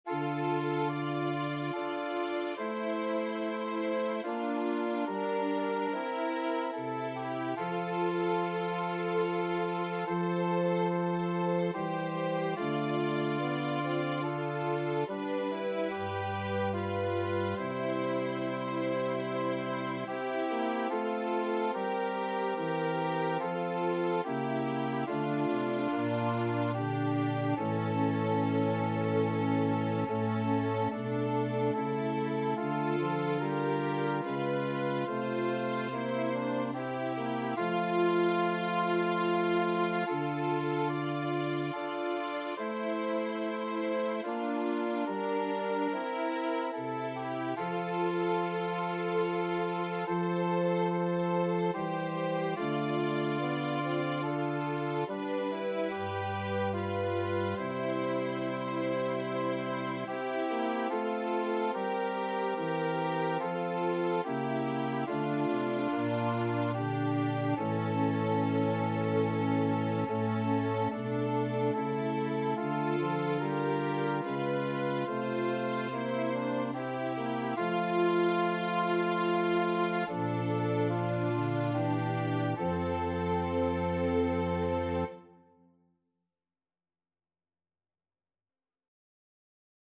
4vv Voicings: SA, TB or SATB Genre: Sacred, Hymn
Language: Latin Instruments: Organ